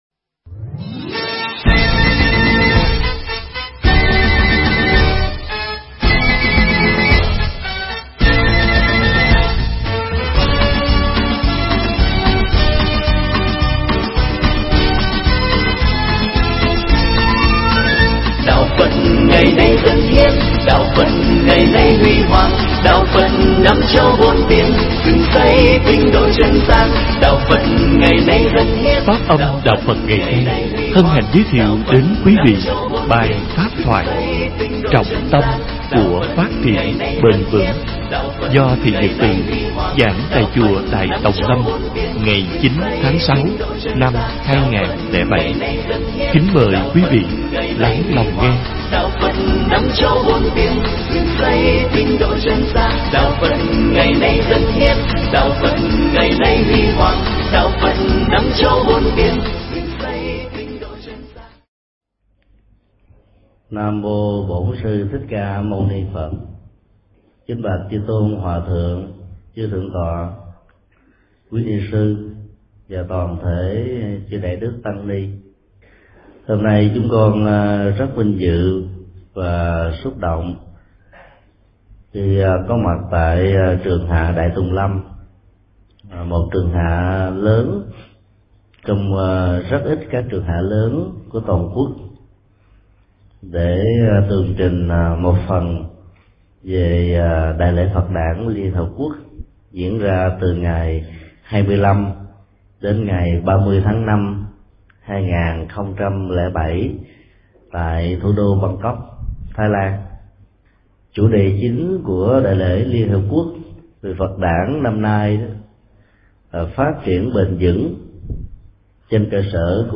Nghe mp3 pháp thoại Trọng tâm của phát triển bền vững do thượng toạ Thích Nhật Từ giảng tại Chùa Đại Tòng Lâm, BRVT, ngày 09 tháng 06 năm 2007.